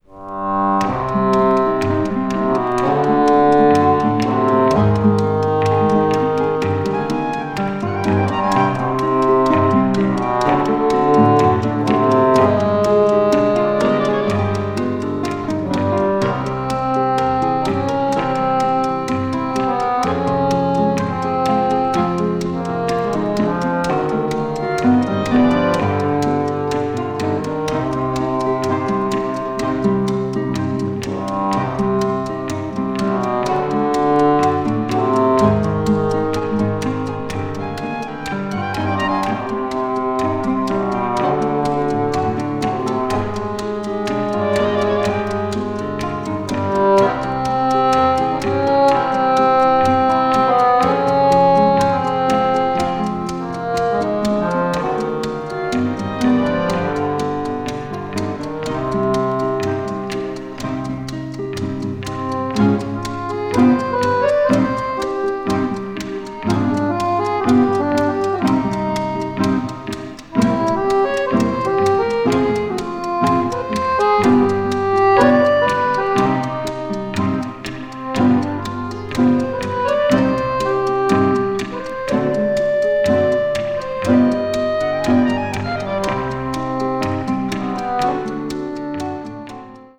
media : EX/EX(わずかにチリノイズが入る箇所あり)
20th century   contemporary   mood   o.s.t.   orchestra